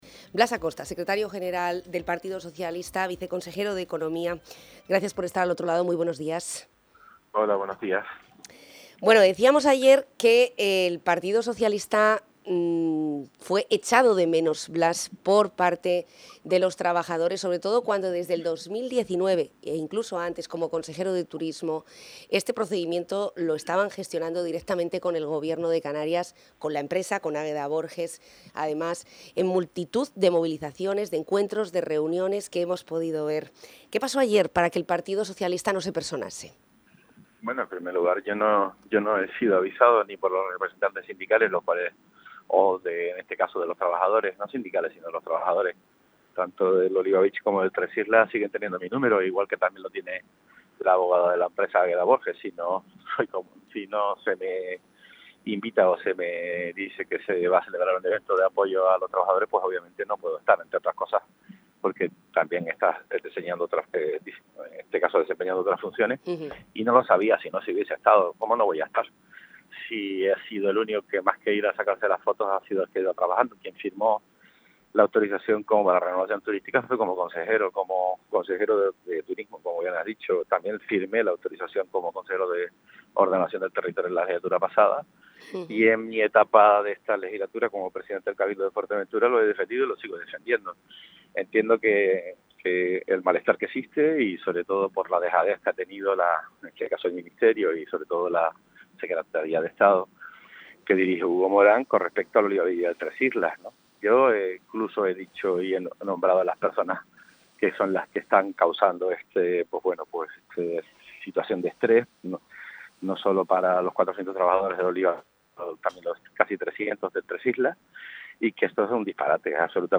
El viceconsejero de Economía del Gobierno de Canarias Blas Acosta, y Secretario General de los socialistas majoreros ha estado en los micrófonos de Onda Fuerteventura para mostrar su apoyo a los trabajadores.